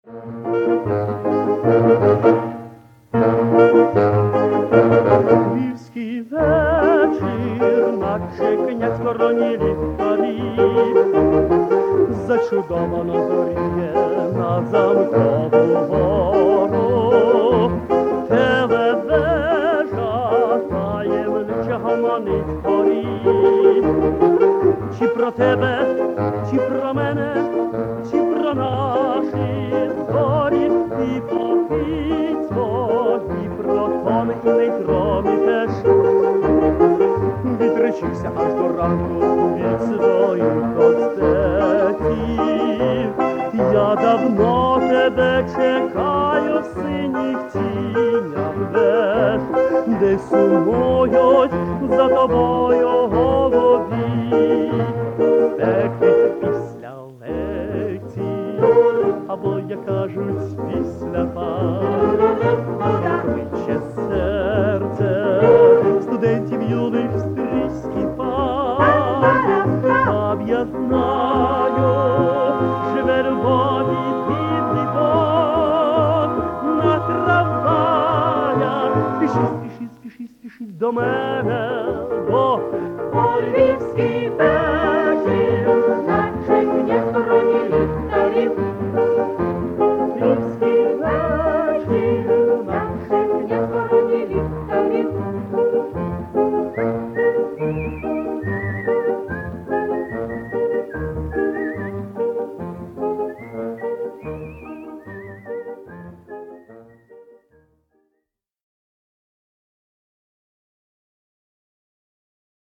Эстрадный ансамбль (ВИА)